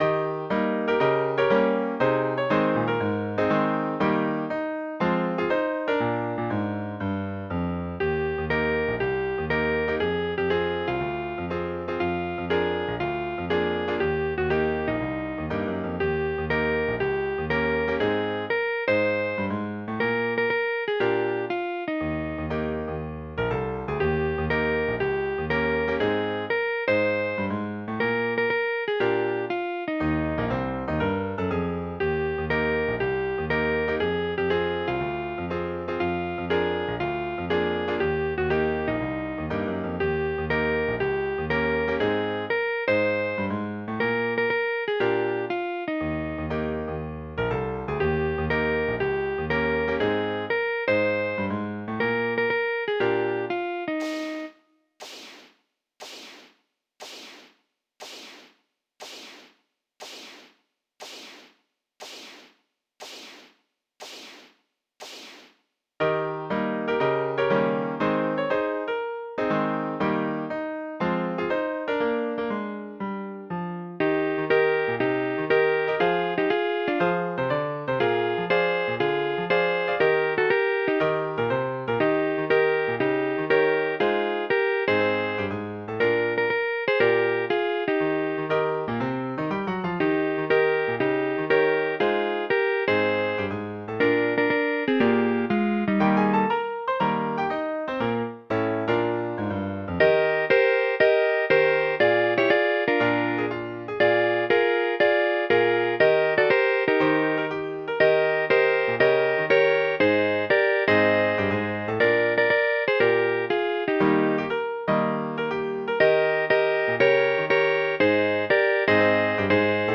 Чудова музична п'єса!
12 12 Гарно, весело.